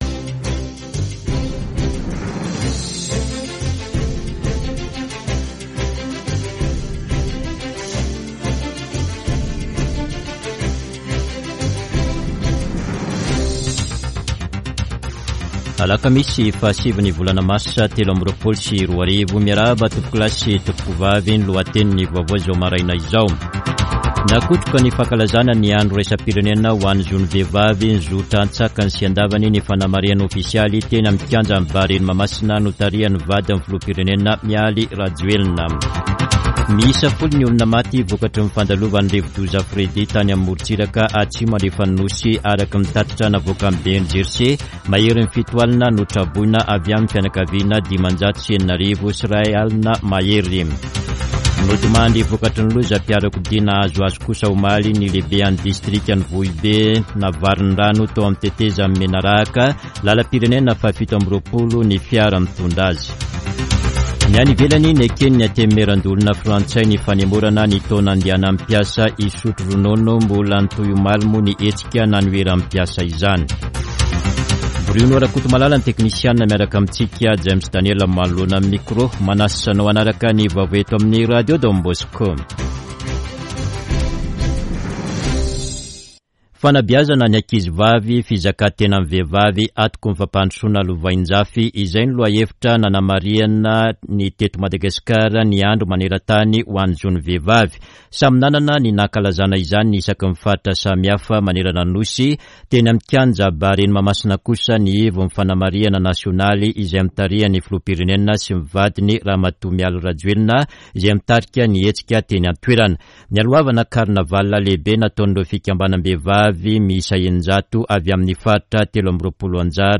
[Vaovao maraina] Alakamisy 09 marsa 2023